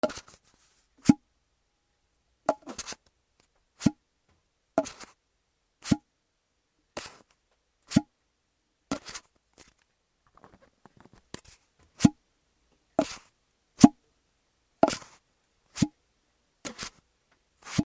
筒を開けたり閉めたり
/ K｜フォーリー(開閉) / K56 ｜小物の開け閉め
『シュポ』